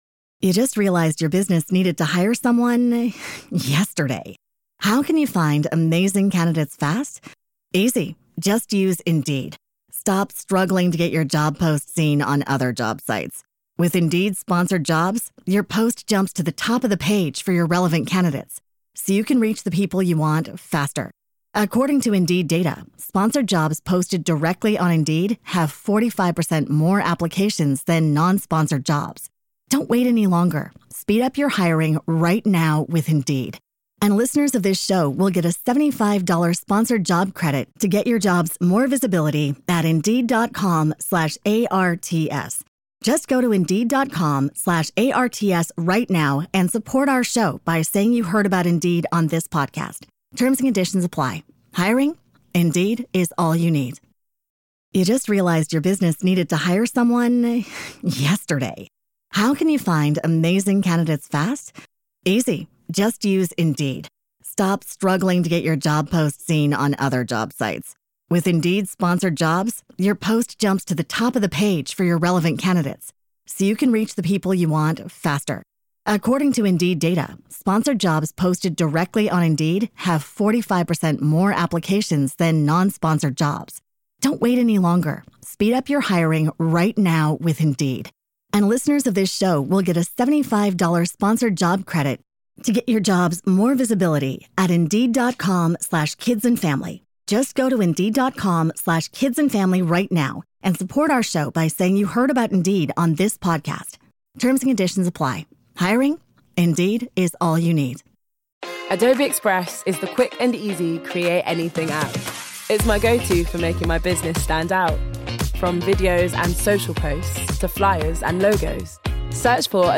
SPOILER FREE chat about some of their latest book reads. We cover a lot of genres so there's sure to be something for everyone!